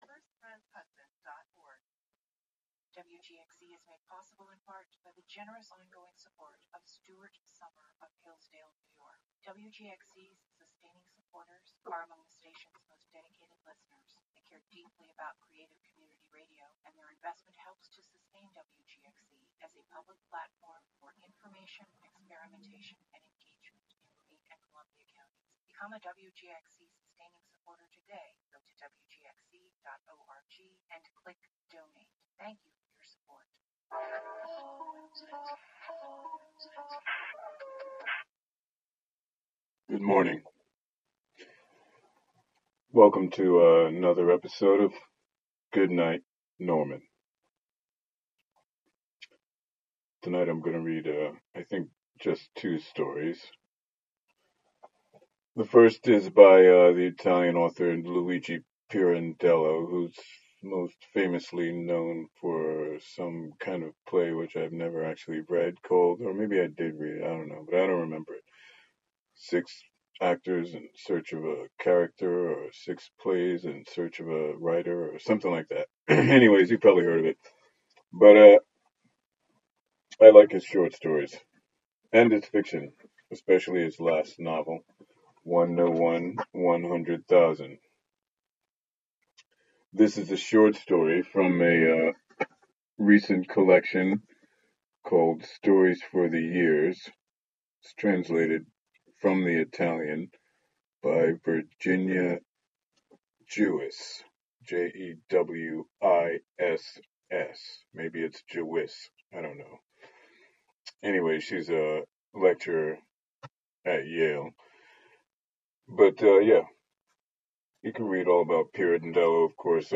just the fictions read for the listener as best as i can read them.